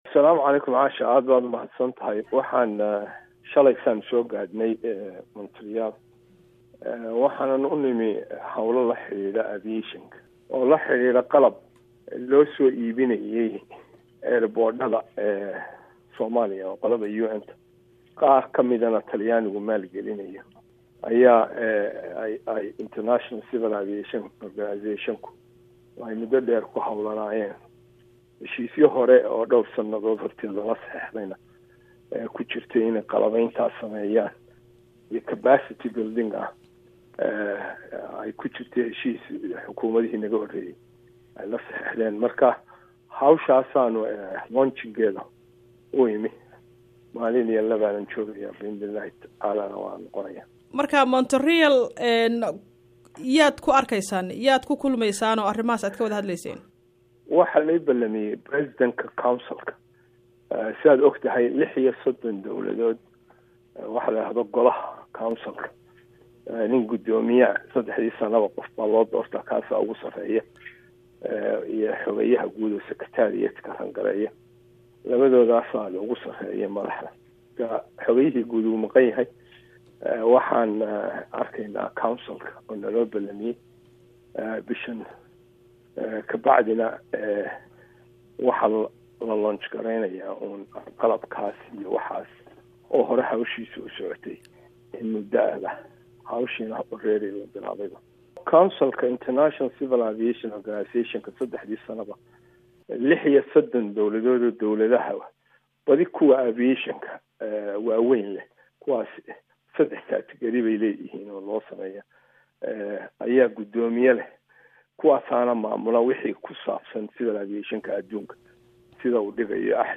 Wareysi: Jangali